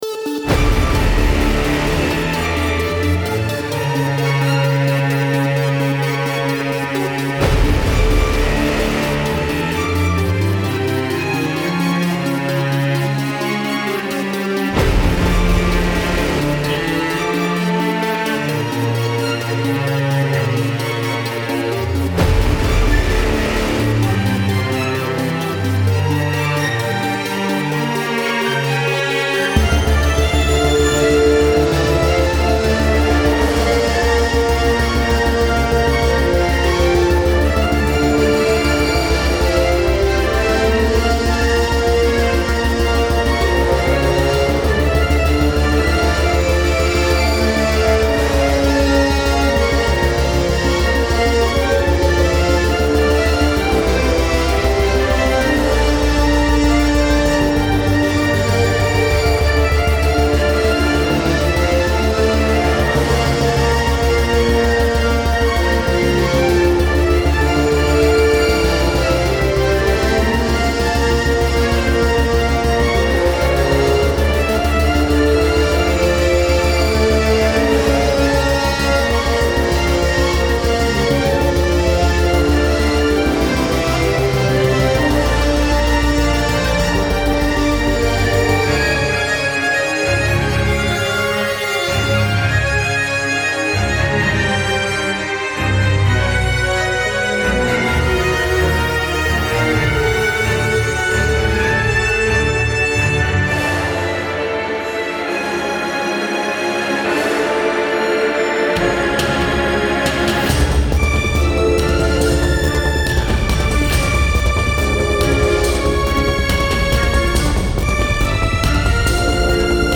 1984 - Orchestral and Large Ensemble - Young Composers Music Forum